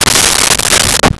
Dresser Drawer Close 02
Dresser Drawer Close 02.wav